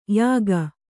♪ yāga